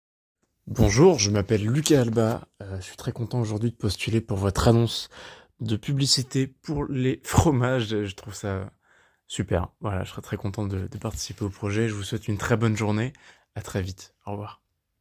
Présentation pub fromage